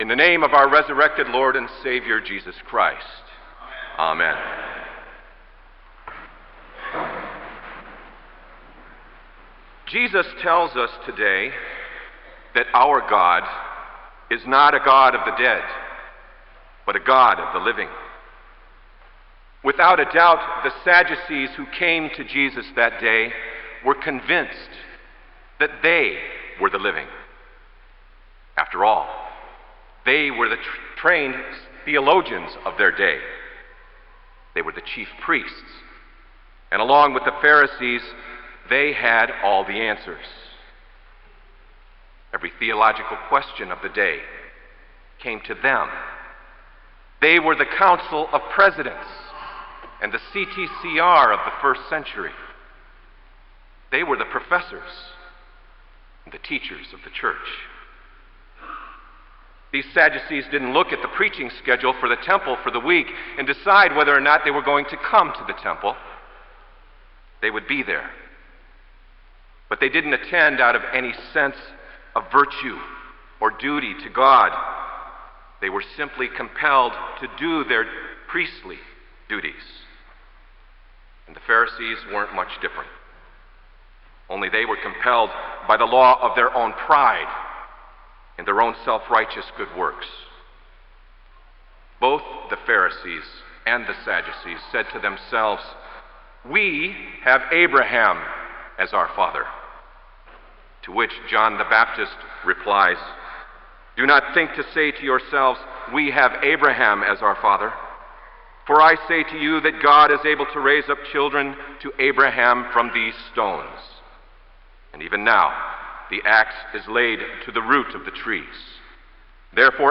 Kramer Chapel Sermon - May 14, 2003